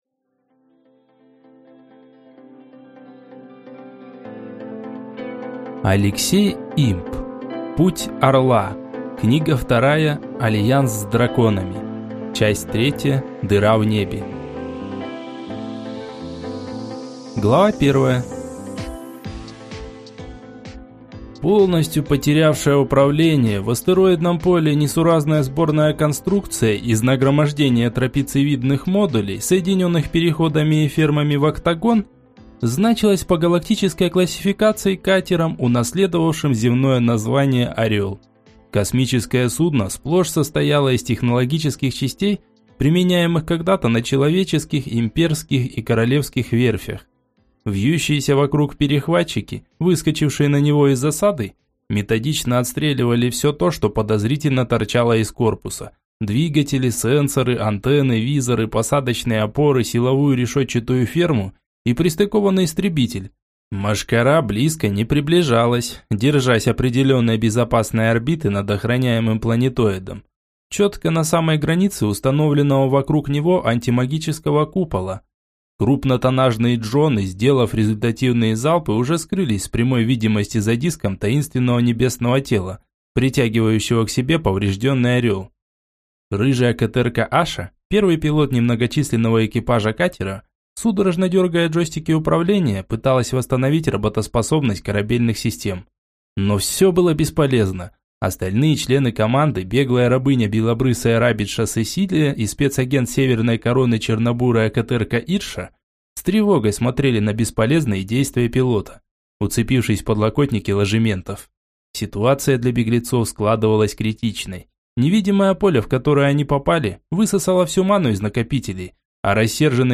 Аудиокнига Путь Орла. Книга 2. Альянс с Драконами | Библиотека аудиокниг